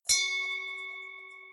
bell.ogg